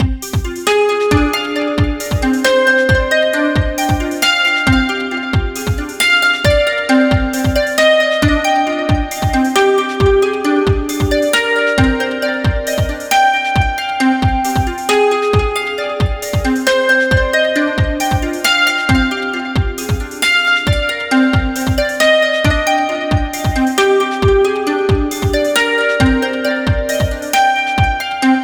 Examples: A simple 4-step sequence is played, and the tone colour is varied from a separate 16-step CC track:
Let's try it with a 16 note track:
Or Interval=4 and Repeat=1:
mbseqv4_demo_progitv_len16r.mp3